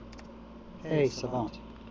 wake-word